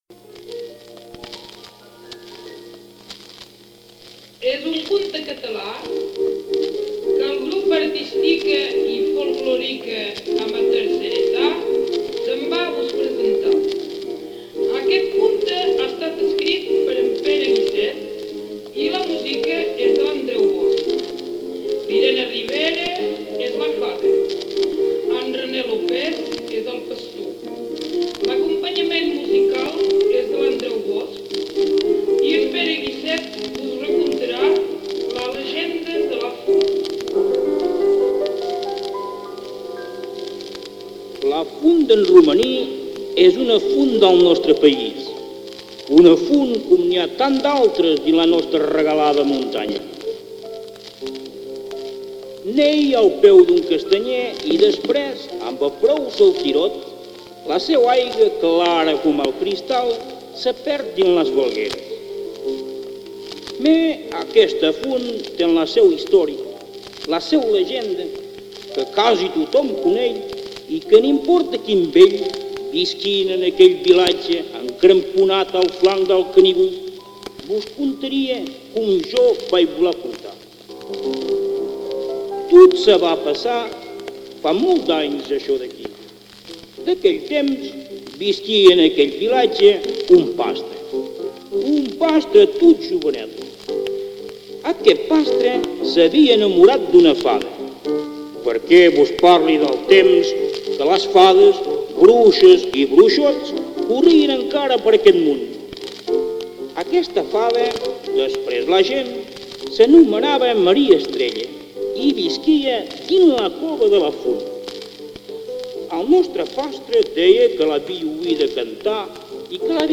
Aqui teniu la versió original cantada